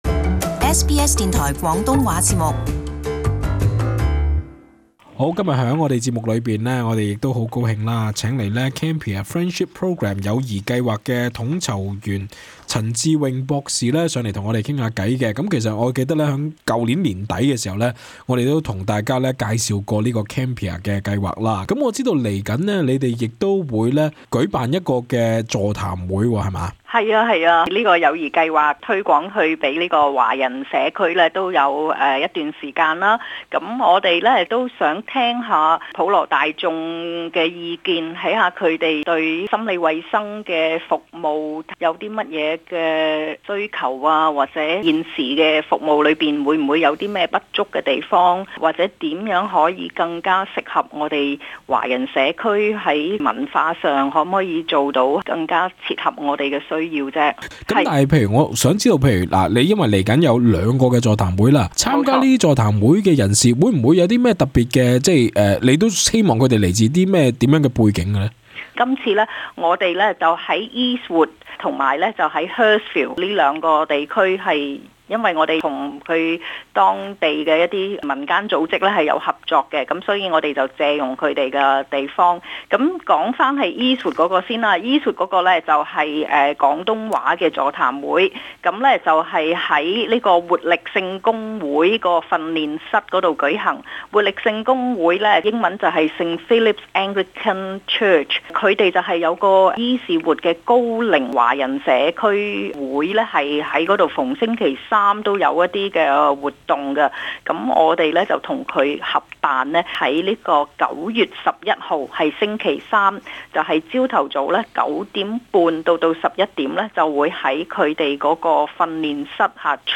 【社區專訪】座談會:你對本地心理及精神健康服務是否滿意?